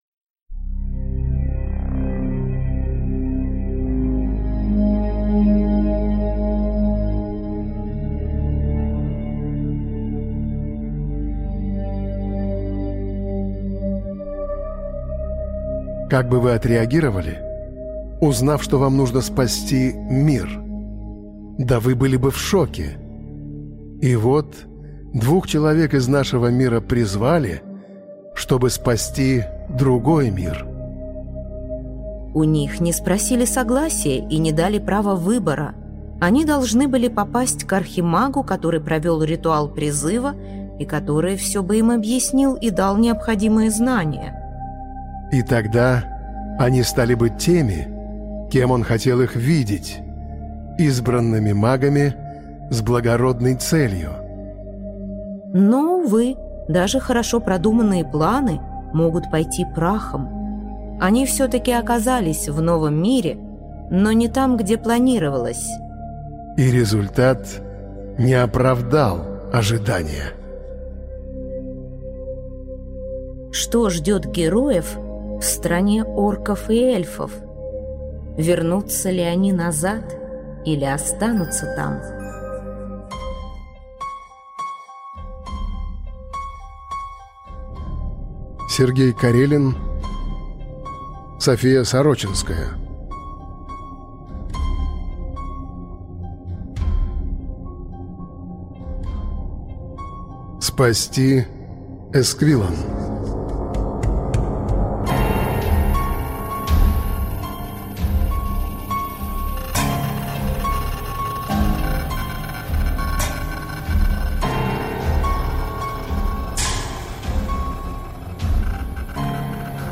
Аудиокнига Спасти Эсквиллан | Библиотека аудиокниг